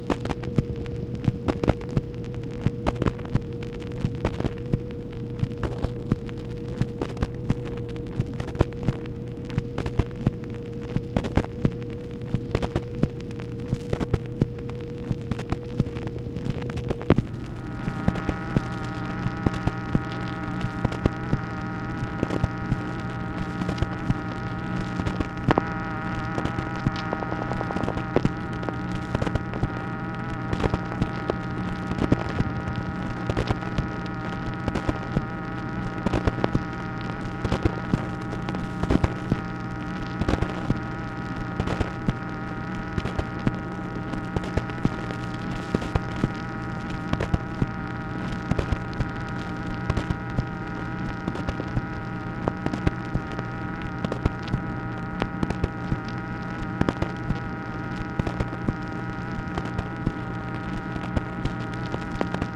MACHINE NOISE, August 4, 1964
Secret White House Tapes | Lyndon B. Johnson Presidency